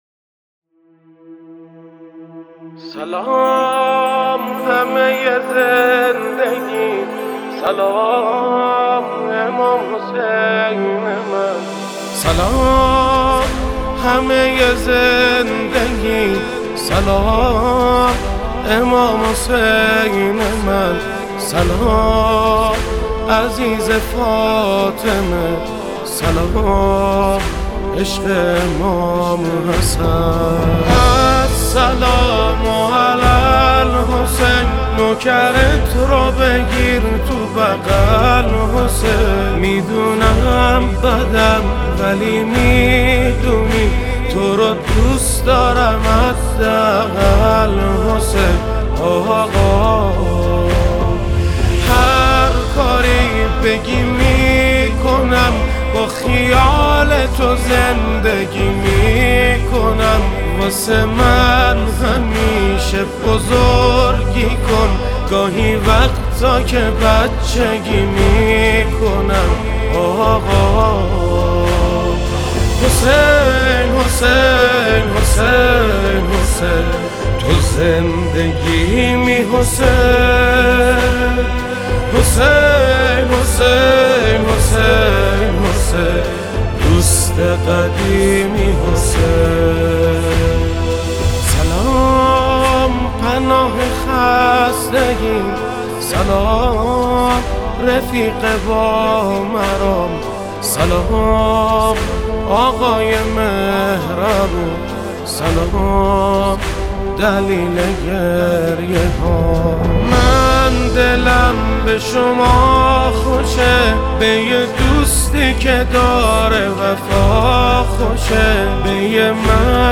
مداحی زیبا و شنیدنی